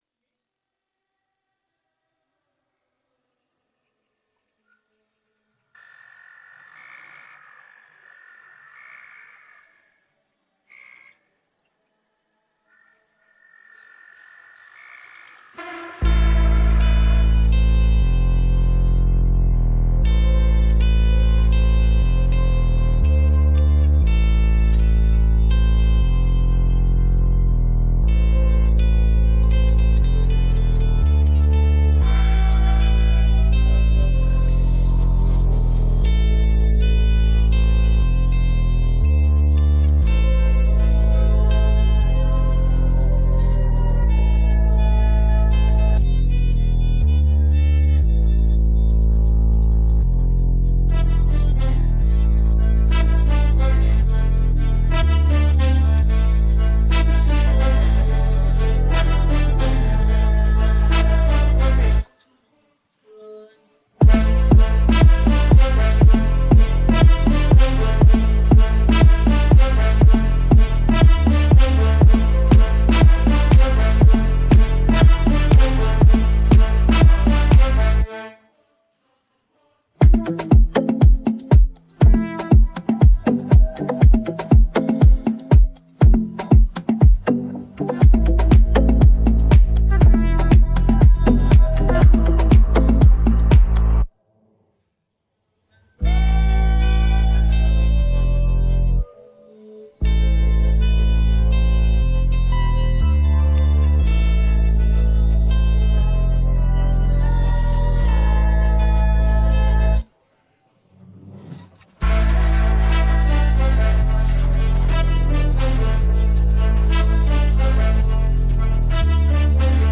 караоке инструментал